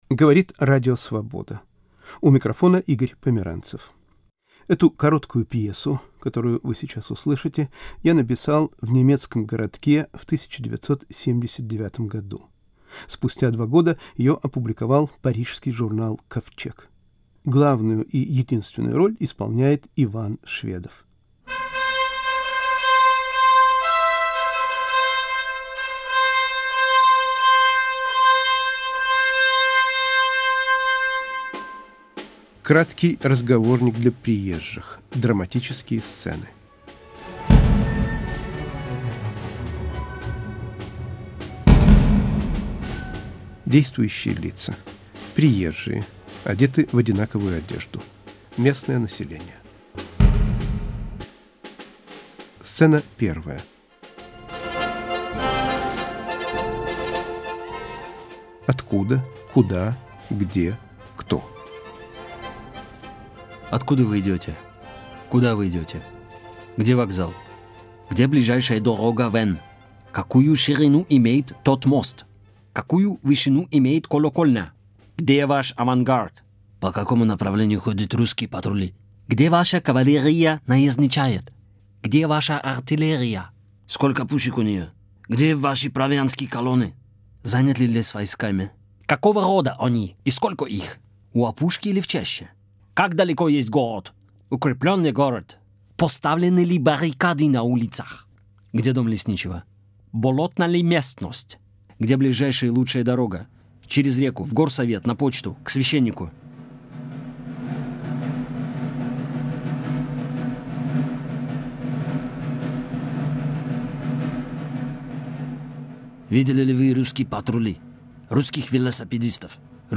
Пьеса